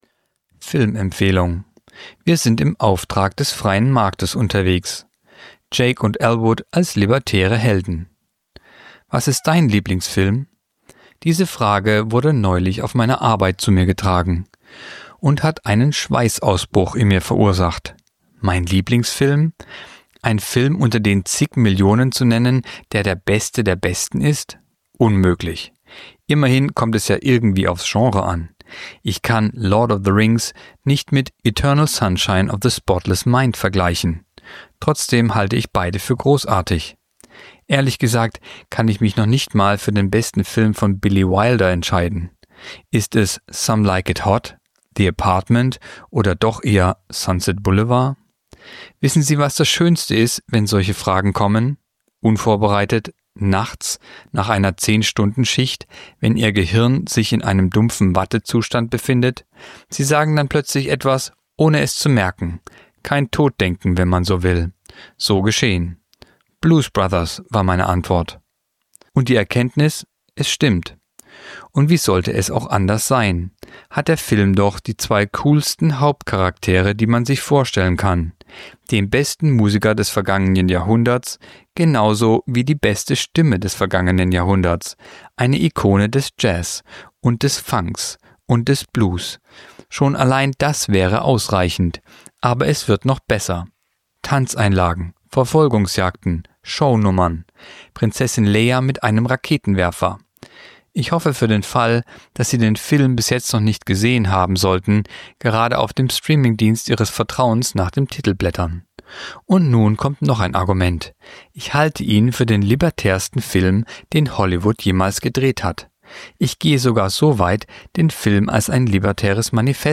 Kolumne der Woche (Radio)„Wir sind im Auftrag des freien Marktes unterwegs!“